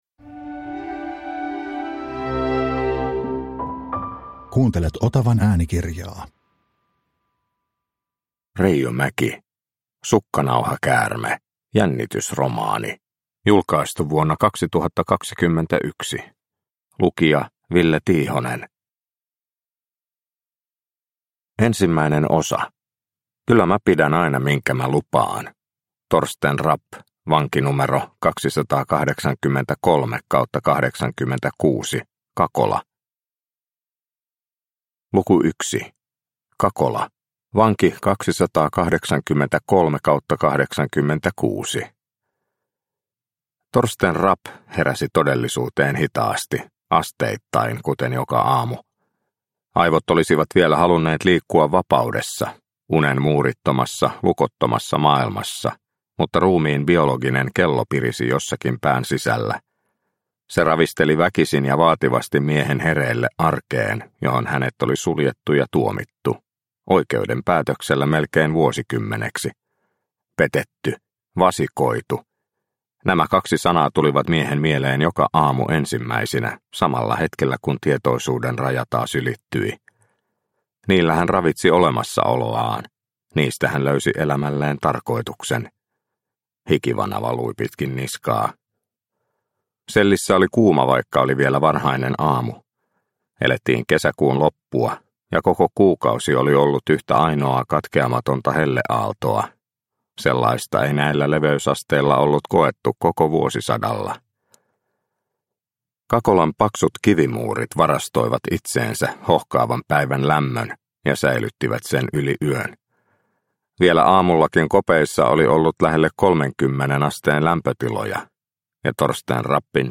Sukkanauhakäärme – Ljudbok – Laddas ner